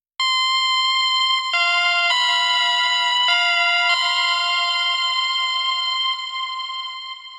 描述：简单的结晶性合成器旋律
Tag: 130 bpm Chill Out Loops Synth Loops 1.24 MB wav Key : Unknown